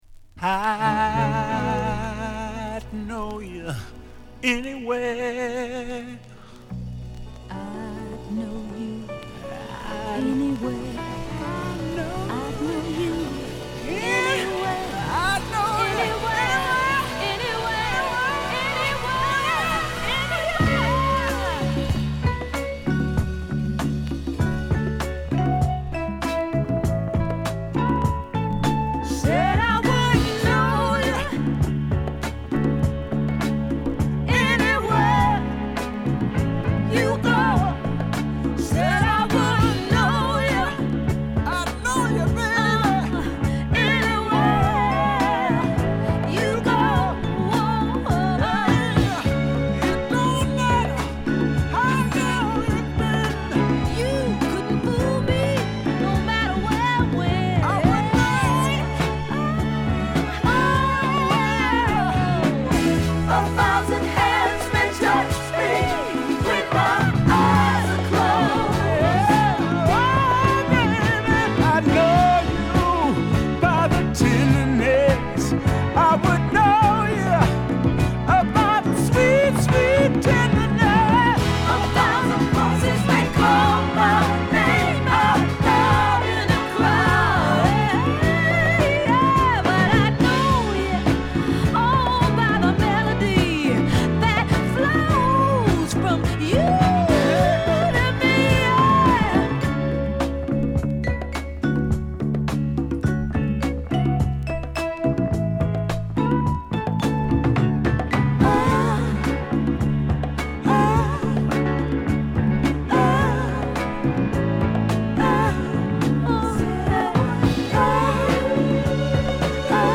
こみ上げアレンジが素晴らしいメロウソウルを披露